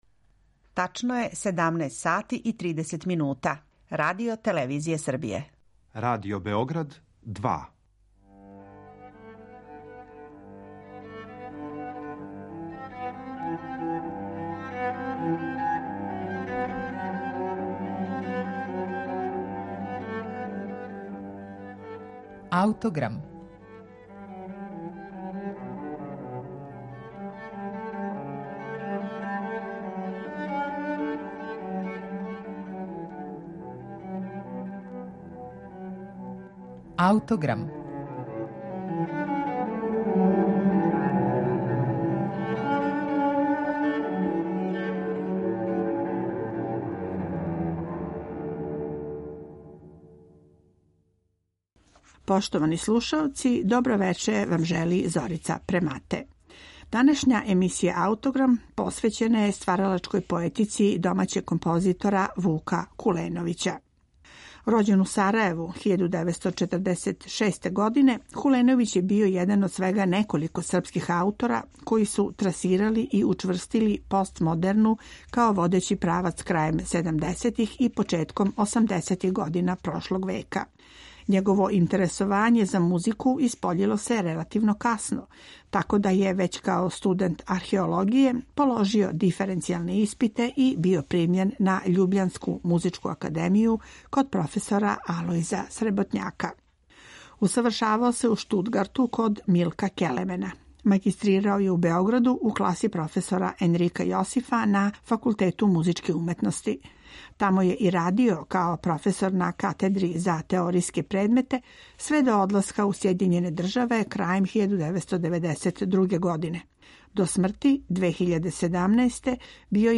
Биће то његова VIII симфонија
Снимак потиче са премијерног извођења дела